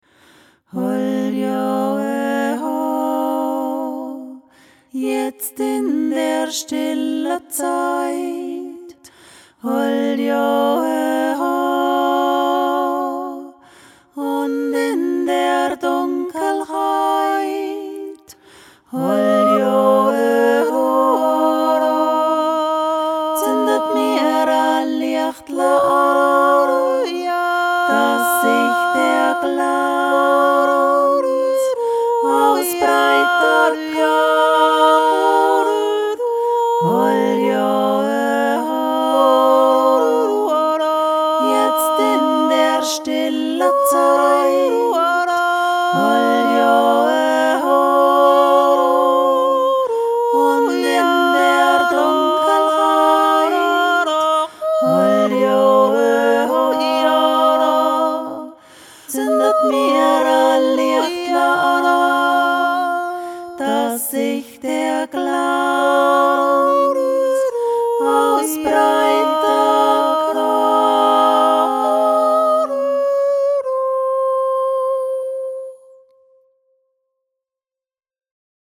Der Dezember JOIK
dezember-joik.mp3